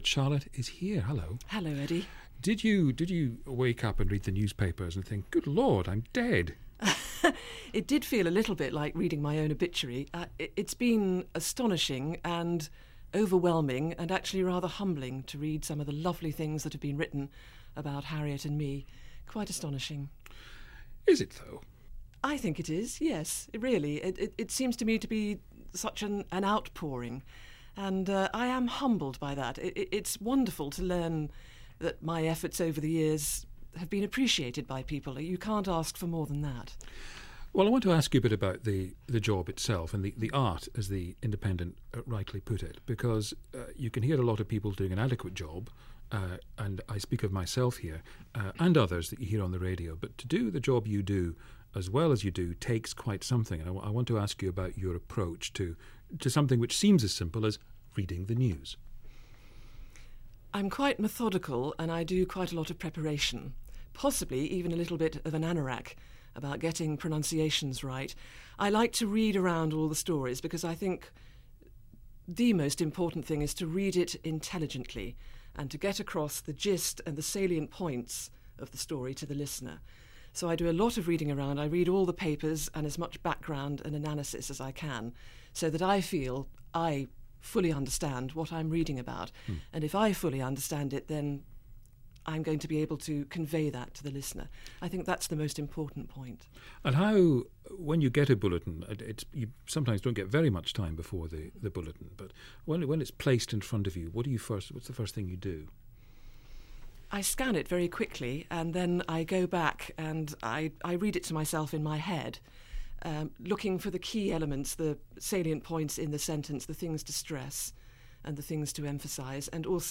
Charlotte Green tells Eddie Mair that she has been overwhelmed by the response to her decision to leave Radio 4. She also talks about her "methodical" approach to newsreading and admits to being a giggler.